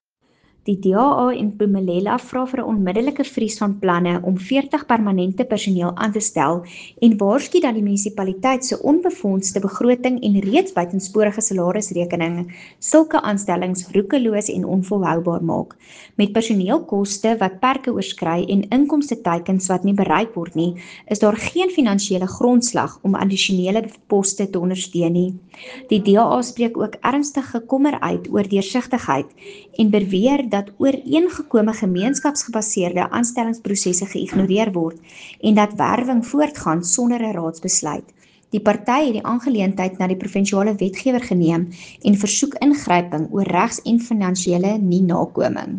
Afrikaans soundbite by Cllr Anelia Smit, and